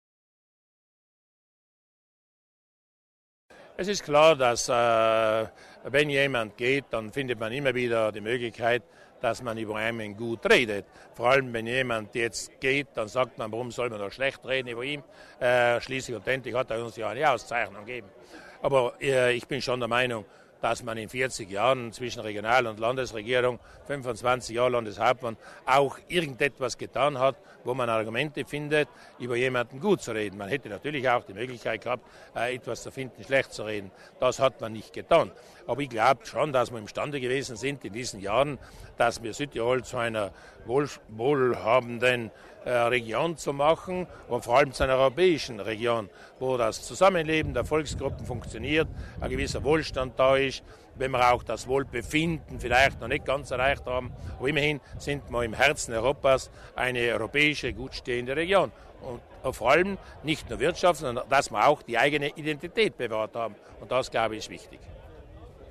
Landeshauptmann Durnwalder erläutert die Gründe für die Verleihung des Großen Verdienstorden des Landes